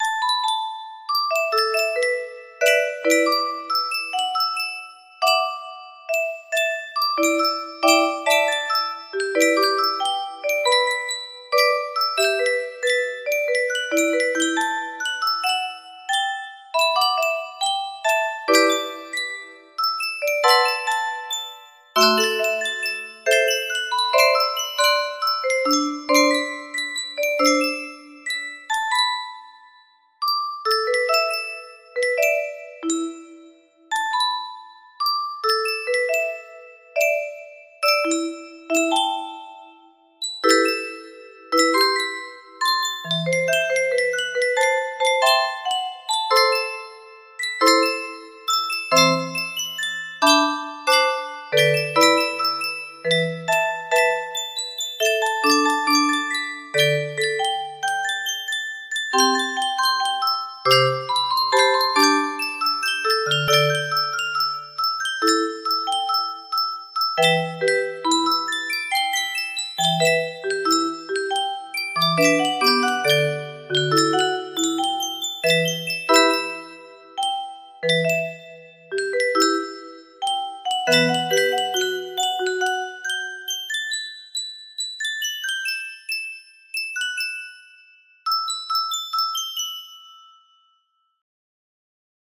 music box melody
Full range 60